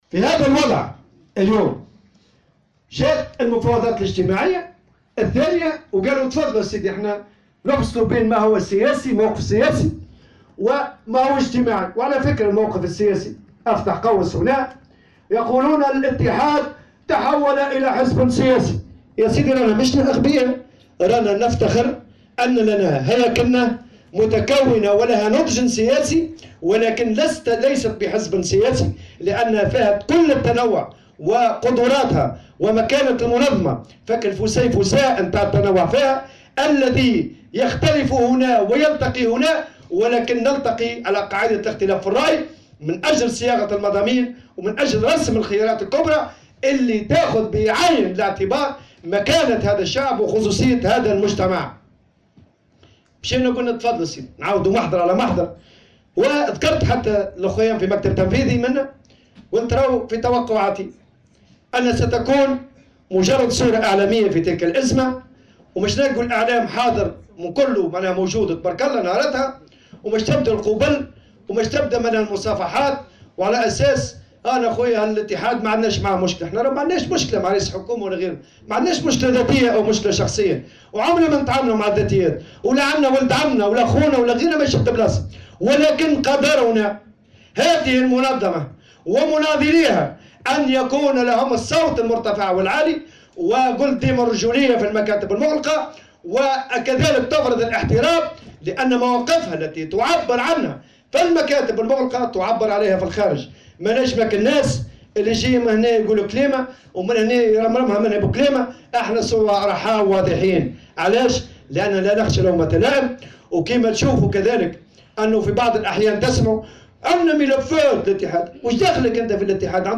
وقال الطبوبي في كلمة ألقاها اليوم الثلاثاء، خلال افتتاح اعمال الندوة الوطنية لقسم الدواوين والمنشآت العمومية باتحاد الشغل المعقدة بالحمامات، إن المنظمة الشغيلة لا تخشى أية تهديدات أو اتهامات من أي طرف كان، نظرا لما تتميز به هياكلها من صراحة ووضوح في المواقف.